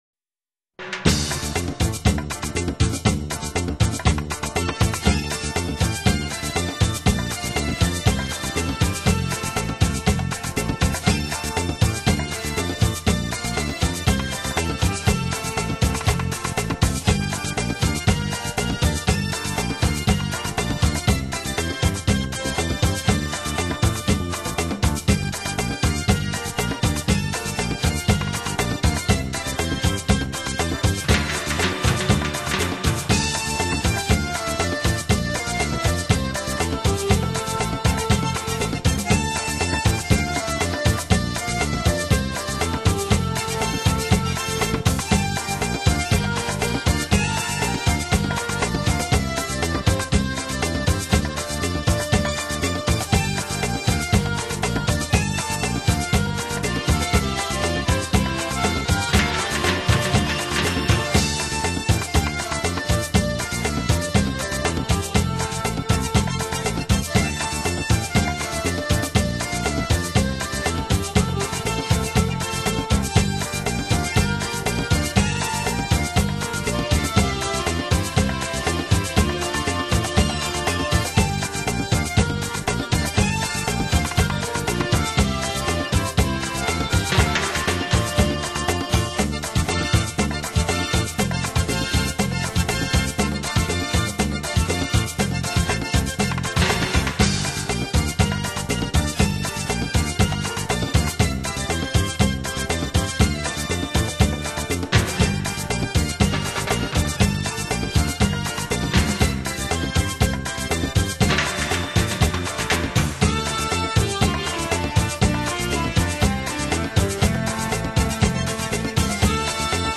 Genre: Instrumental, Accordeon